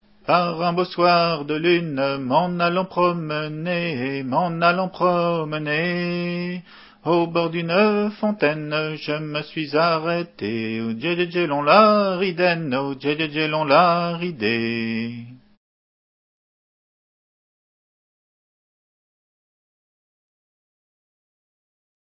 Hanter dro
Entendu au fest-noz de Lorient en août 90